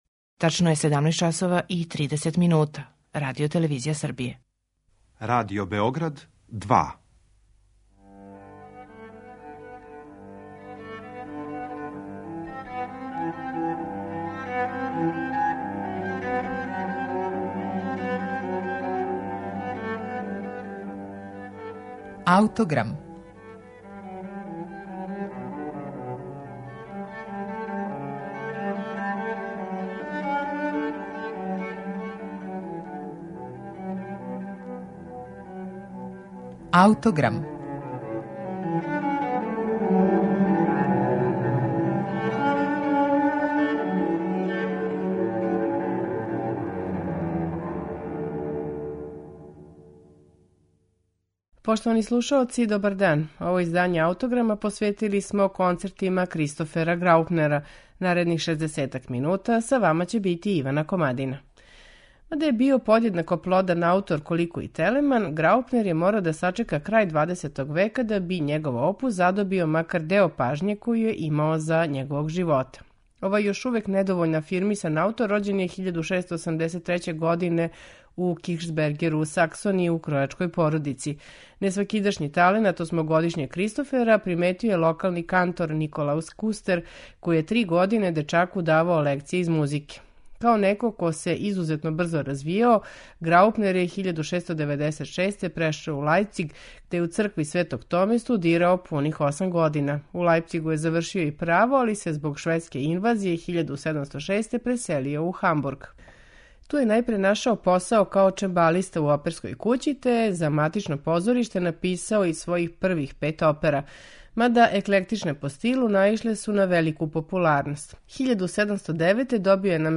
Највећи број намењен је дувачким инструментима, а пре свега флаути.
четири концерта Кристофа Граупнера слушаћемо у интерпретацији коју су на оригиналним инструментима епохе остварили чланови ансамбла Accademia Daniel